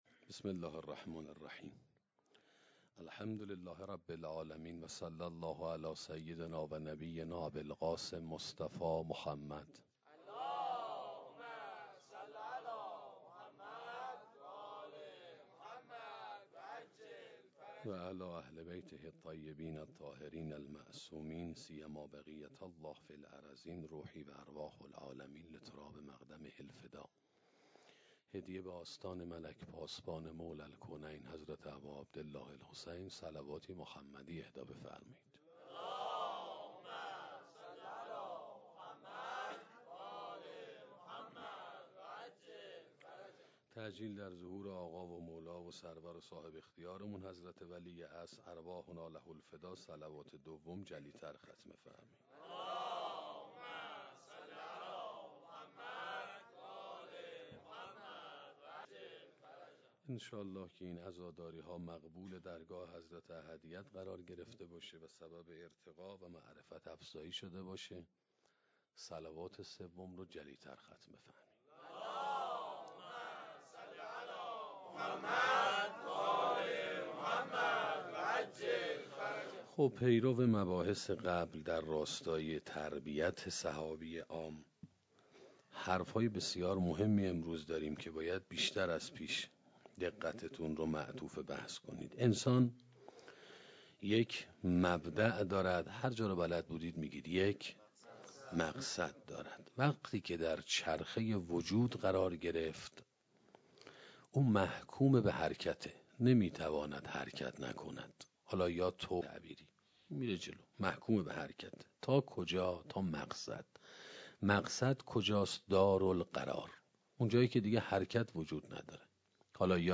سخنرانی عاشورا و انسان حداکثری 1